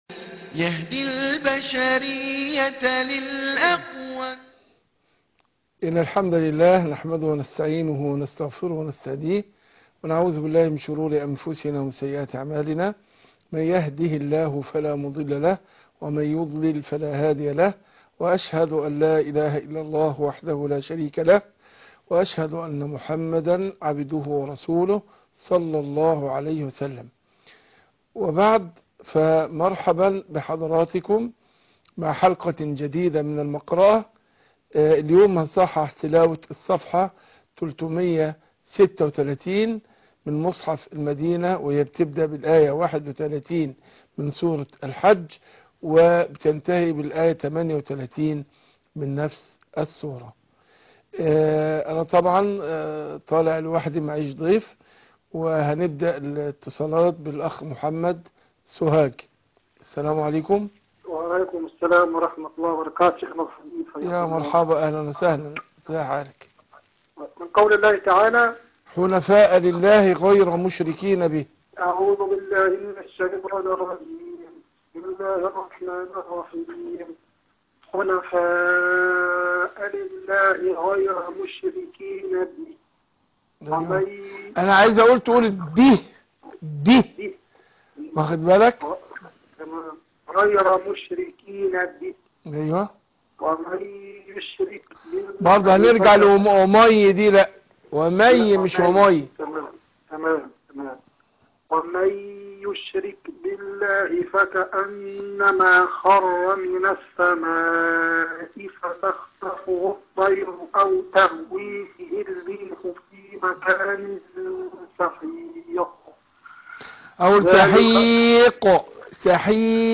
المقرأة - سورة الحج ص 336